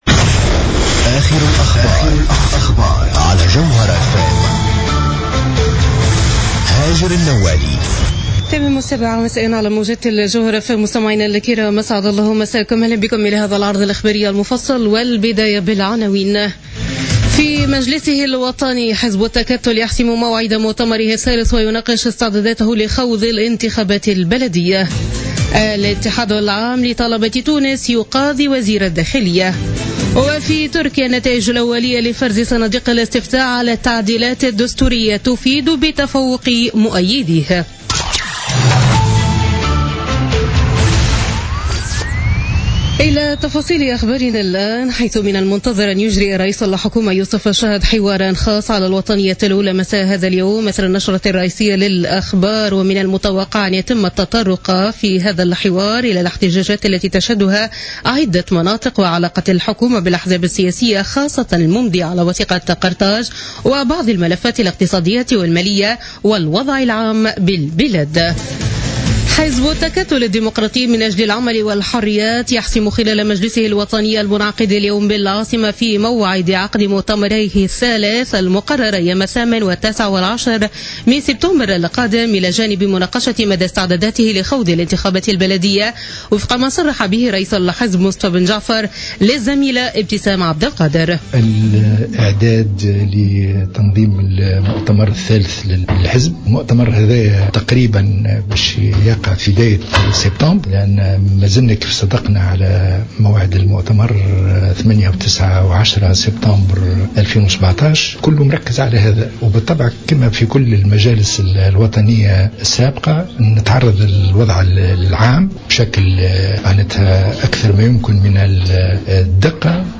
نشرة أخبار السابعة مساء ليوم الأحد 16 أفريل 2017